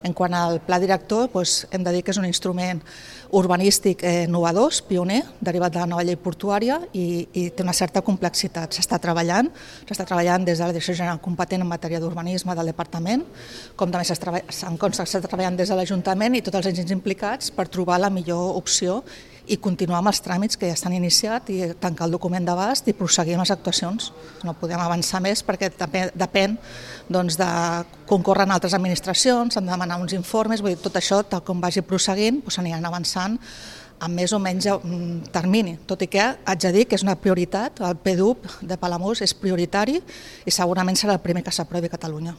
La nova directora general de Ports de la Generalitat, Esther Roca, posa al dia la situació de tot aquest procés en unes declaracions que recull Ràdio Palamós.